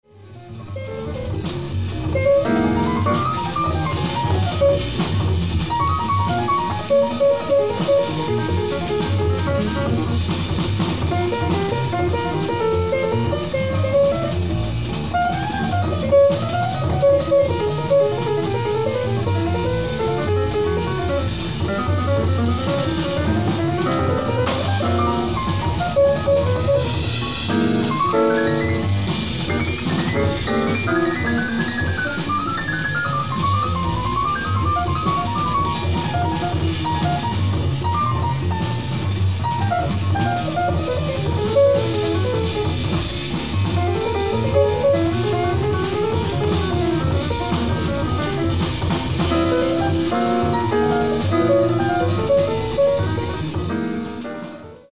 (p, fl, perc)